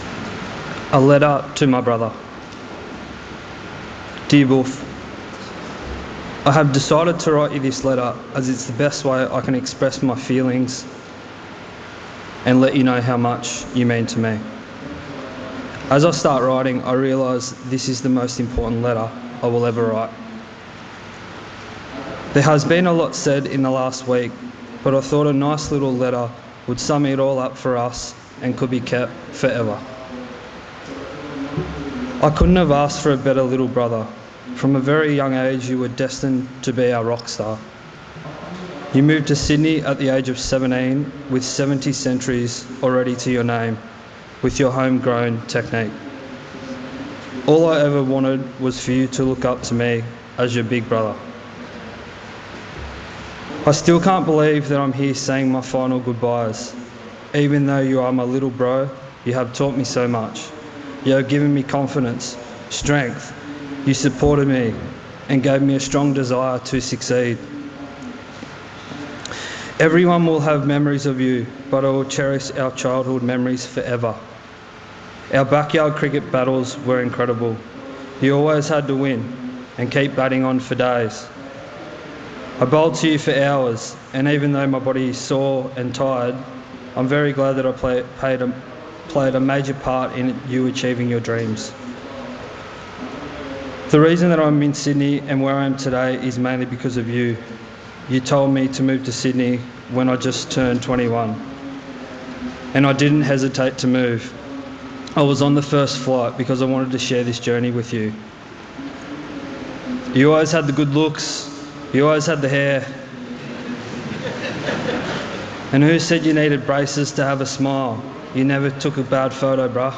delivers his eulogy.